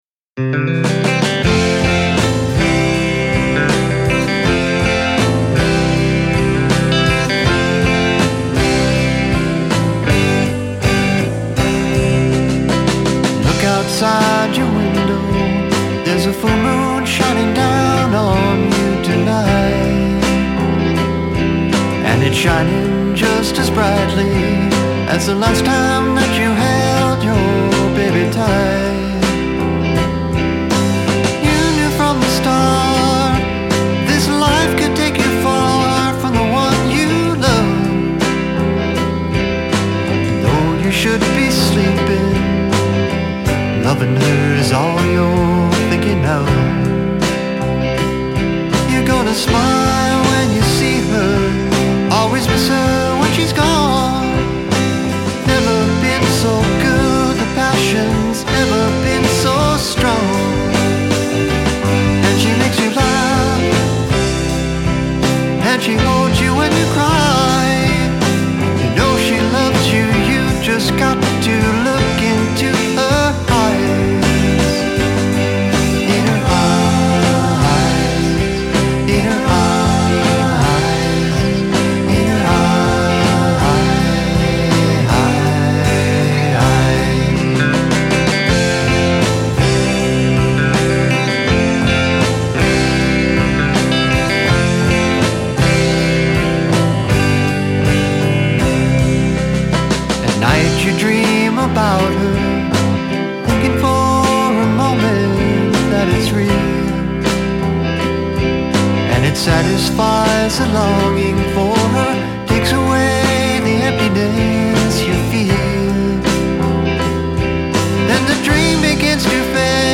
I like your singing voice. Nice bass level.
There seems like a buildup of frequencies around the 1K point. Seems like a lot of stuff competing in that range.